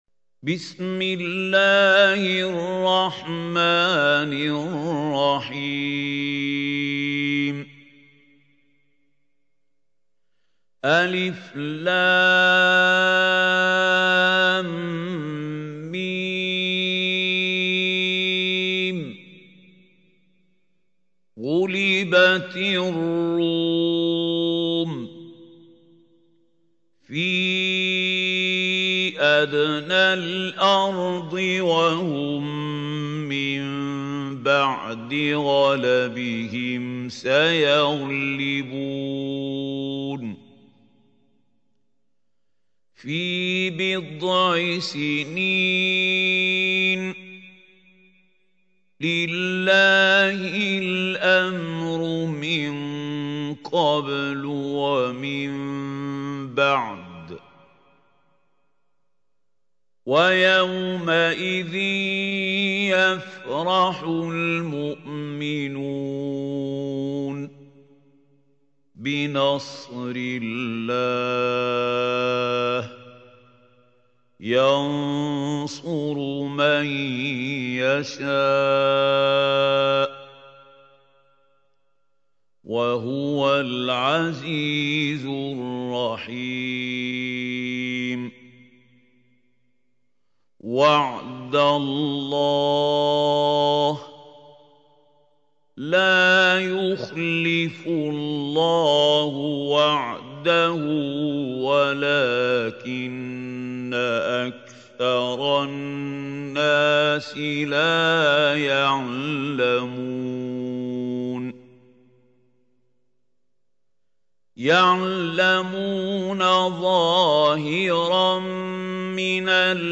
سورة الروم | القارئ محمود خليل الحصري